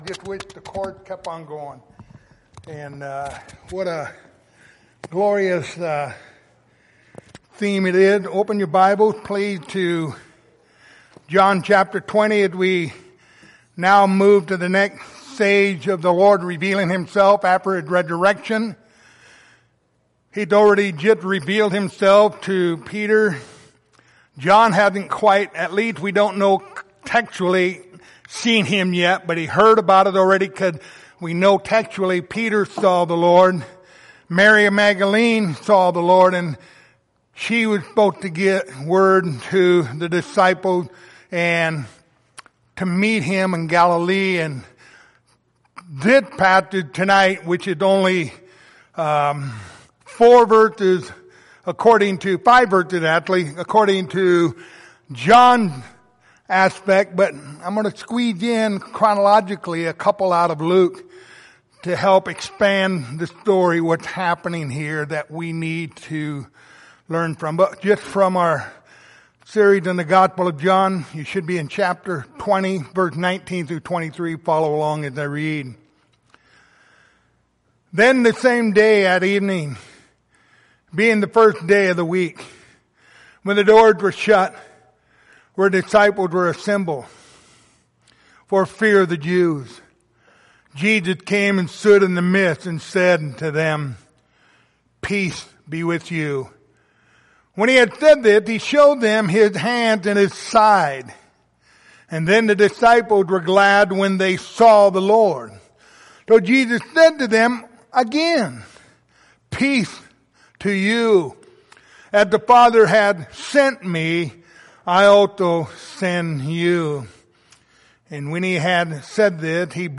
The Gospel of John Passage: John 20:19-23 Service Type: Wednesday Evening Topics